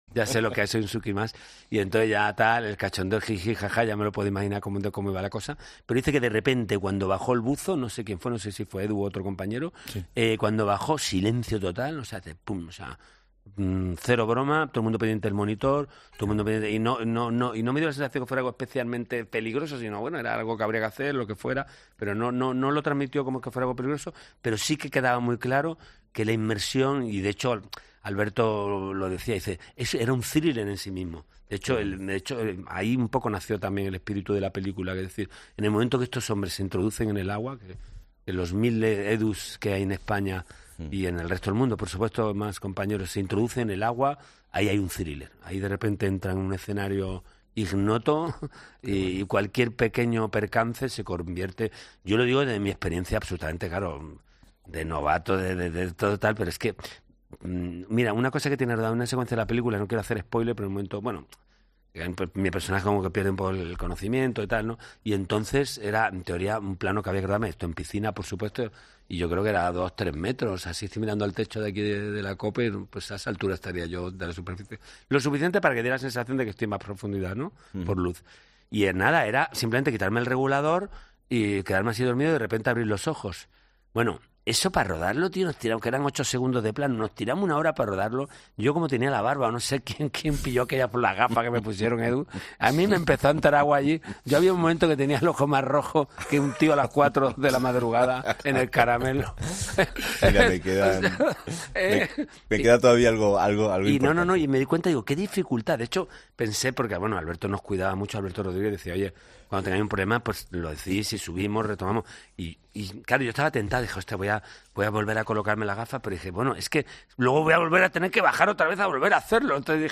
El actor Antonio de la Torre relata su experiencia como buzo en el rodaje de 'Los Tigres'